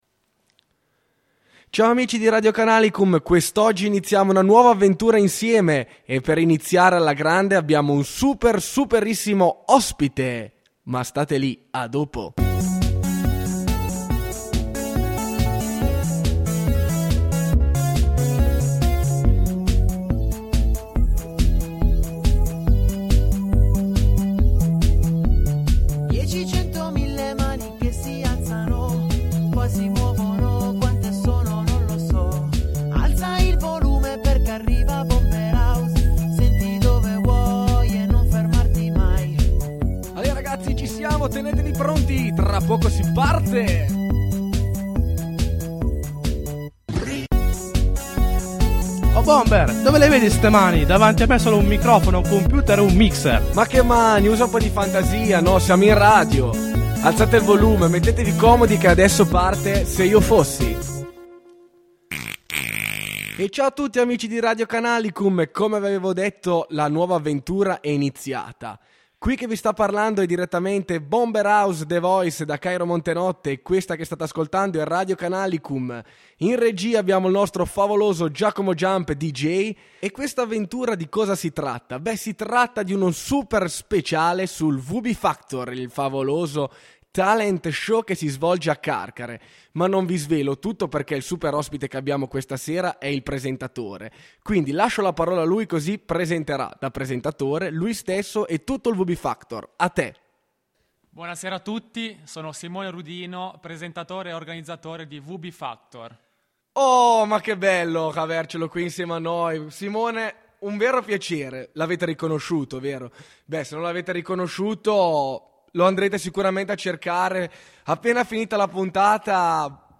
Partecipanti presentatore, cantanti, vocal coach, giudici e staff del talent show